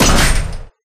Door3.ogg